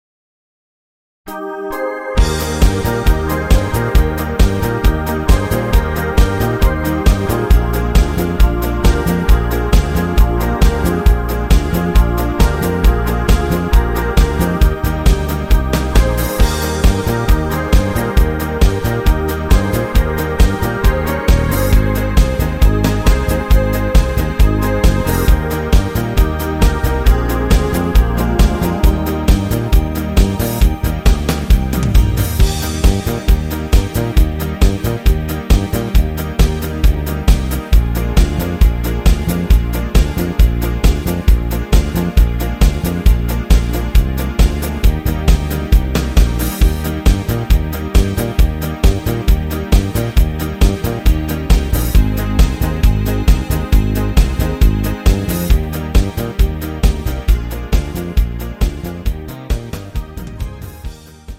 Disco-Marsch Mix